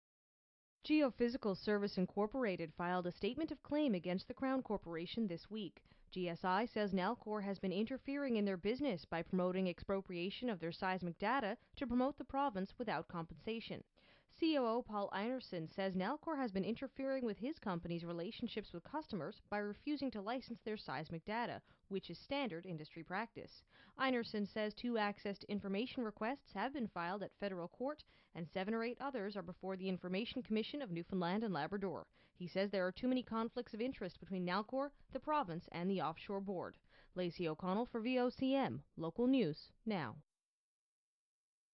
reports from St John's, Newfoundland